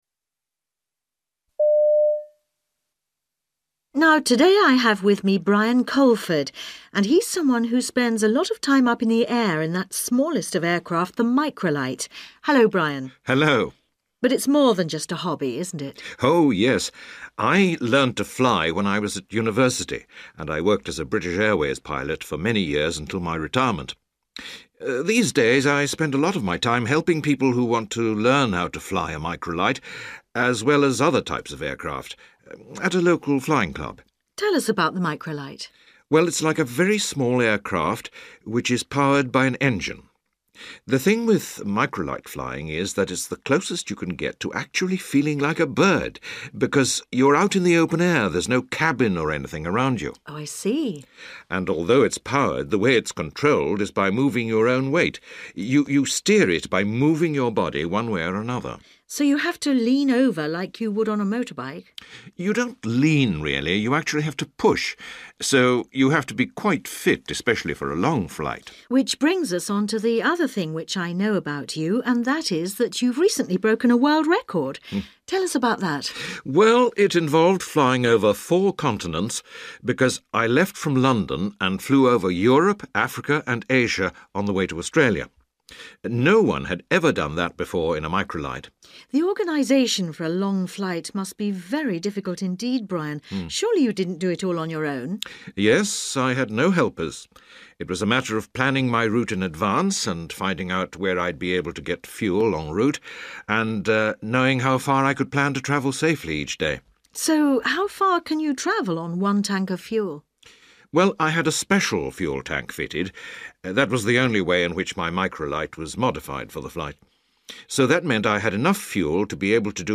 You will hear an interview with a man who enjoys flying in a small aircraft called a ‘microlight’.